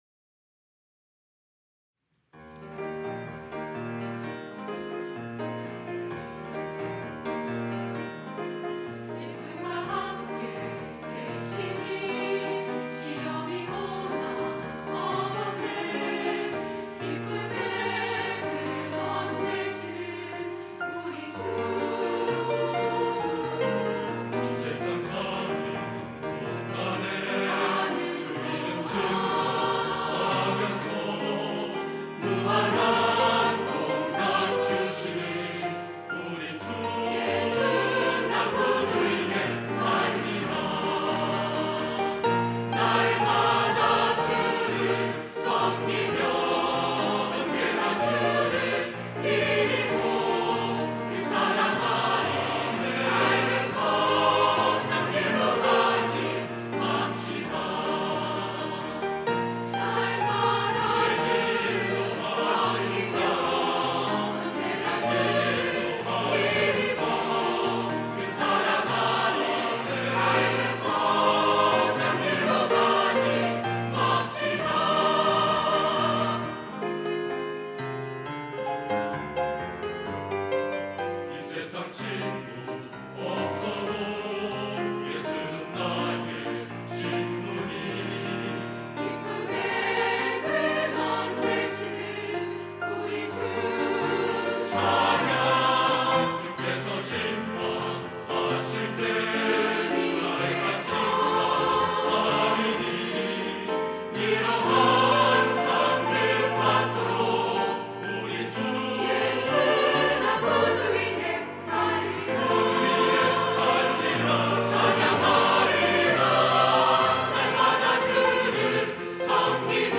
할렐루야(주일2부) - 예수가 함께 계시니
찬양대